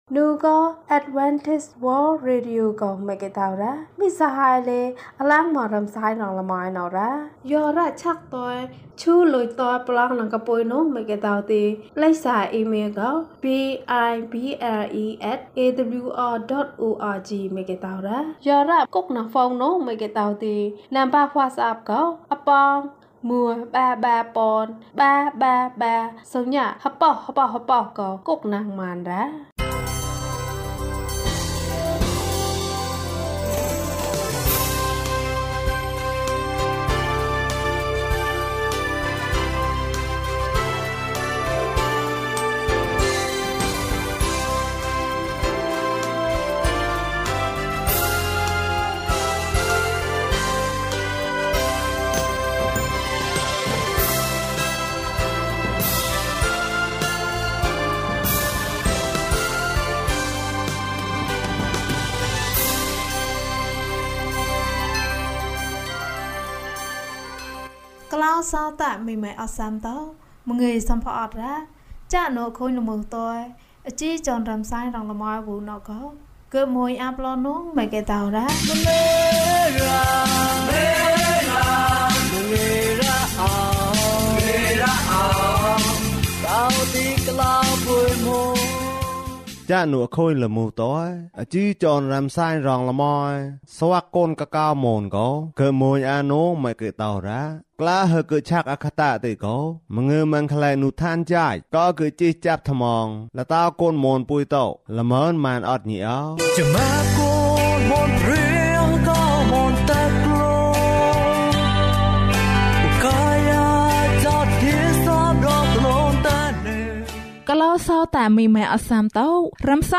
ခရစ်တော်ထံသို့ ခြေလှမ်း။၃၅ ကျန်းမာခြင်းအကြောင်းအရာ။ ဓမ္မသီချင်း။ တရားဒေသနာ။